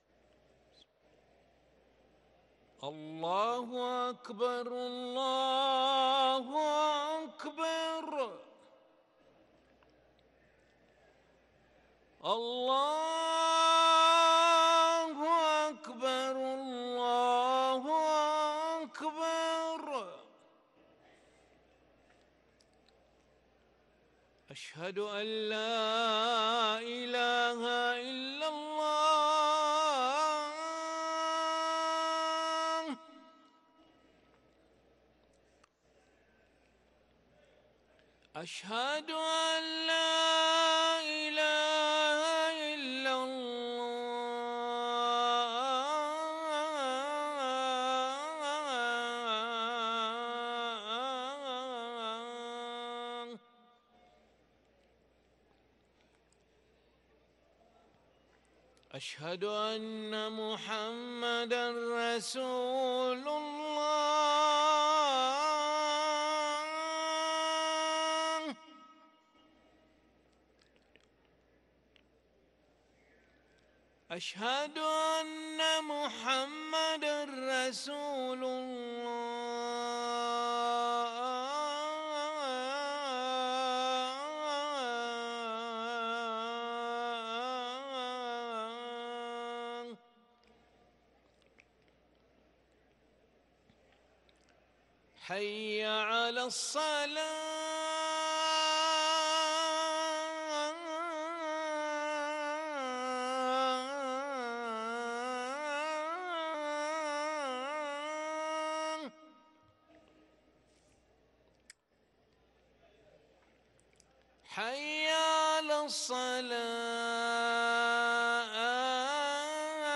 أذان العشاء للمؤذن علي ملا الخميس 21 جمادى الأولى 1444هـ > ١٤٤٤ 🕋 > ركن الأذان 🕋 > المزيد - تلاوات الحرمين